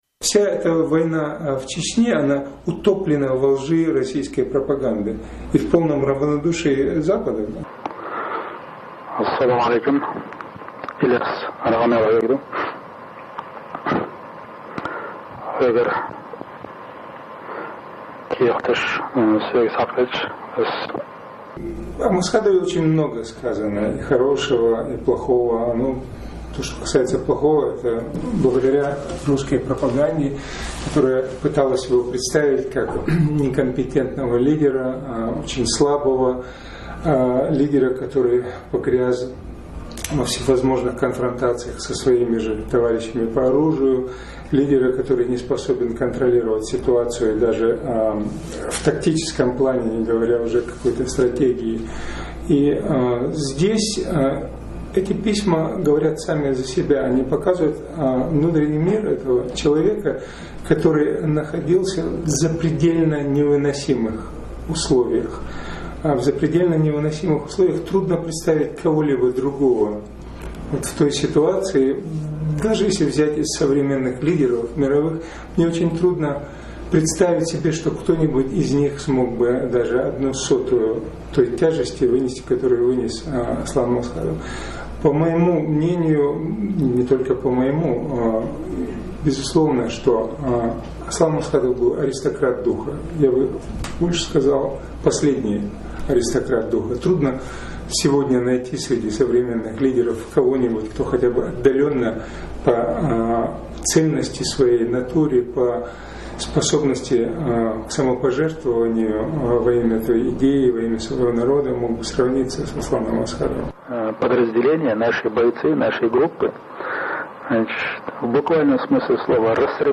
Ахмадов Илесан интервью (оригинал, оьрсийн маттахь)